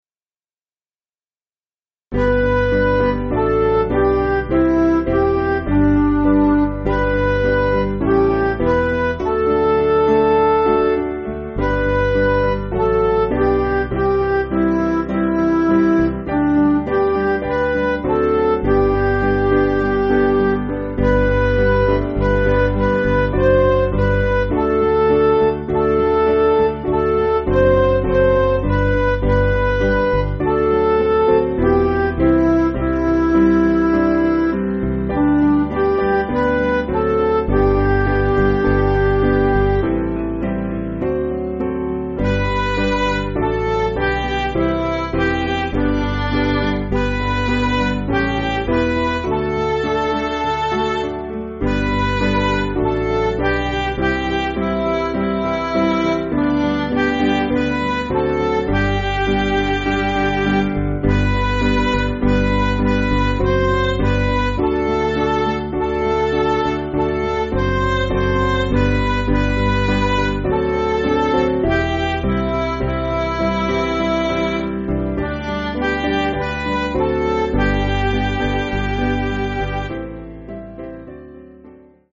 Piano & Instrumental
(CM)   6/G